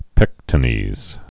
(pĕktə-nēz)